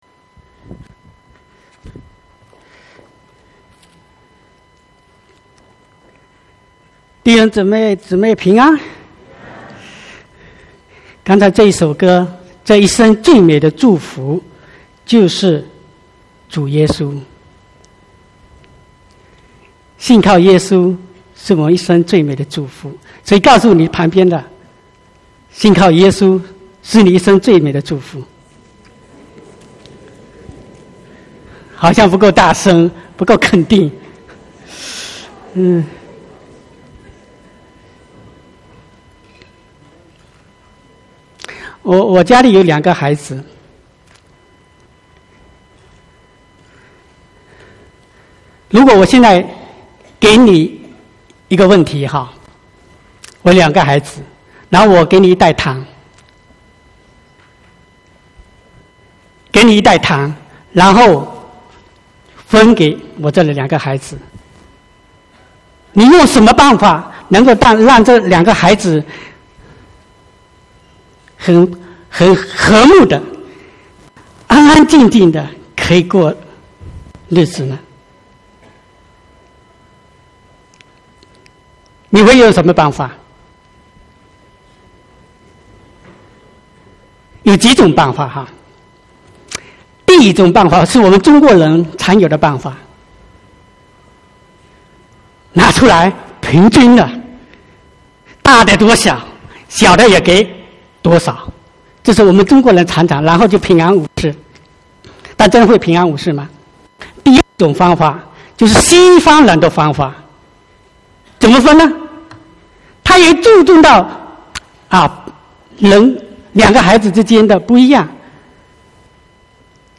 29/9/2019 國語堂講道